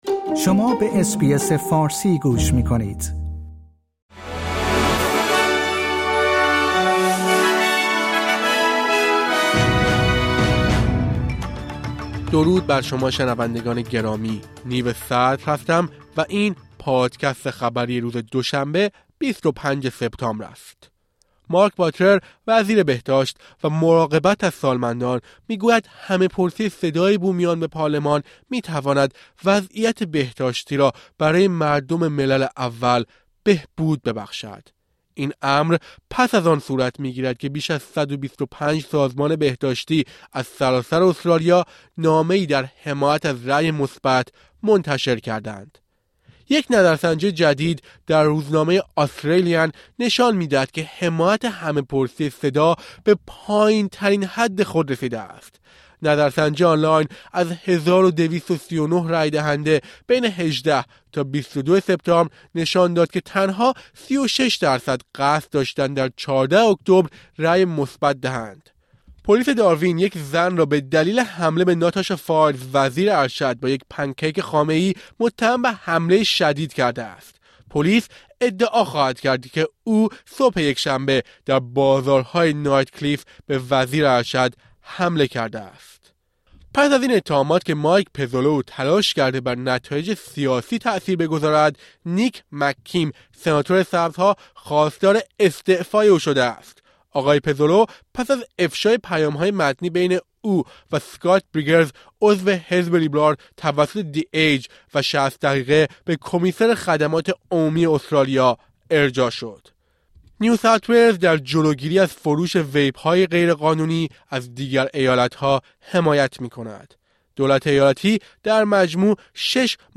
در این پادکست خبری مهمترین اخبار استرالیا و جهان در روز دوشنبه ۲۵ سپتامبر، ۲۰۲۳ ارائه شده است.